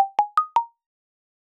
Elastic_Ball.ogg